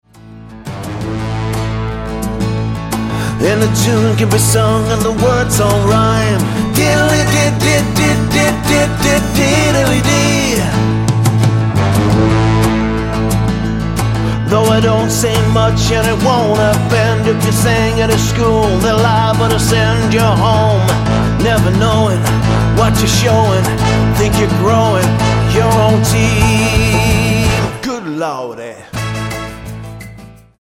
Tonart:A Multifile (kein Sofortdownload.
Die besten Playbacks Instrumentals und Karaoke Versionen .